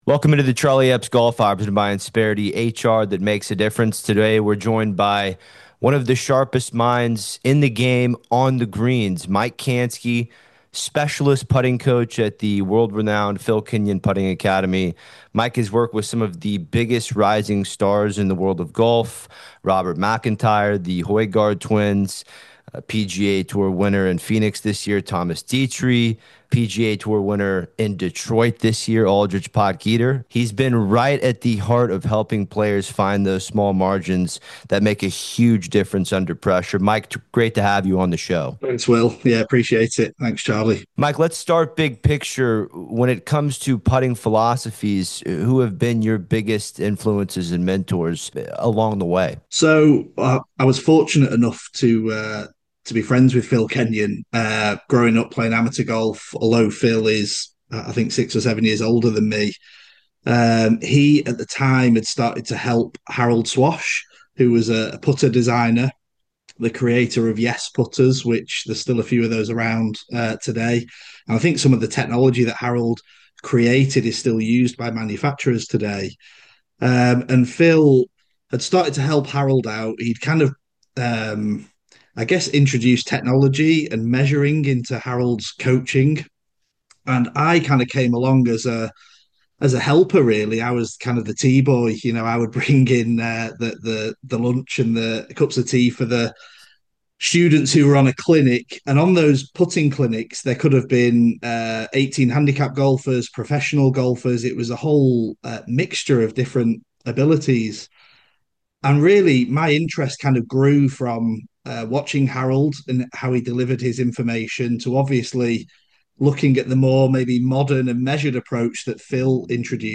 We break down Robert MacIntyre’s recent putting transformation, what separates the next generation of talent, and practical tips for the weekend golfer looking to make more putts under pressure. -- This interview is presented by Insperity — providing HR solutions that truly make a difference.